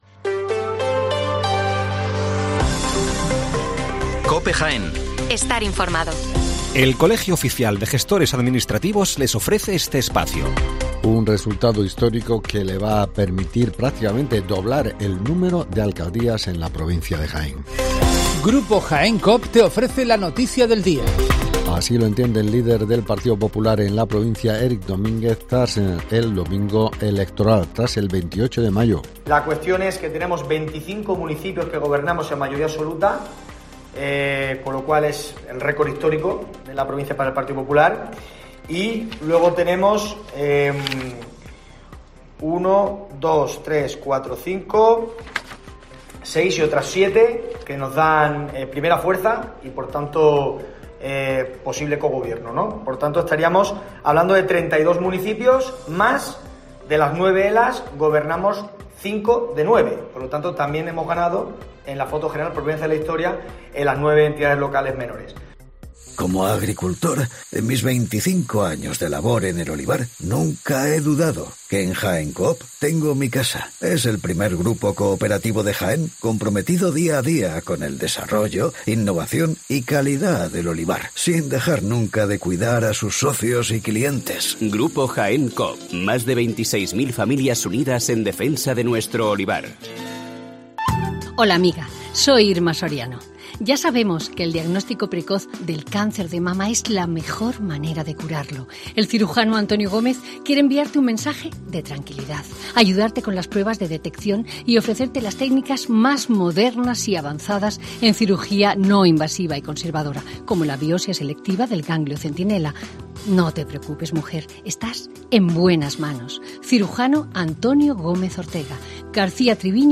Las noticias locales de las 7'55 del 30 de mayo